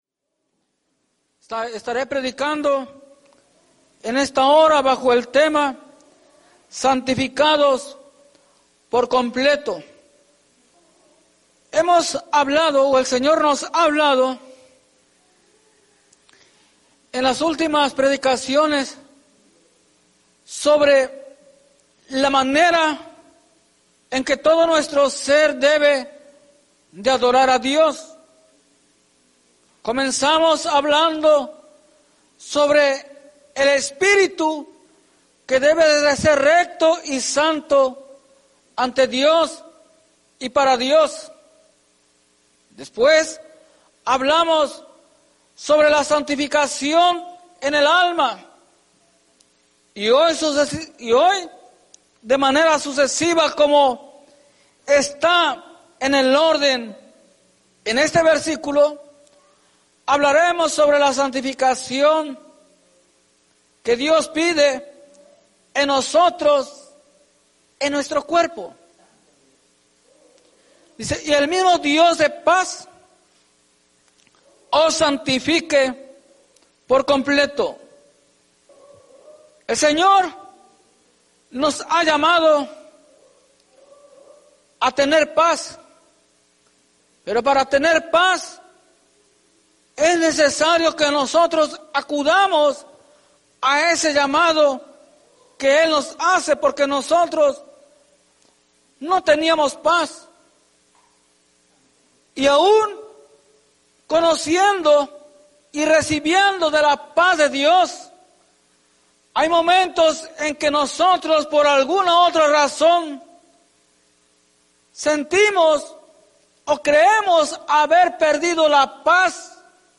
Norristown,PA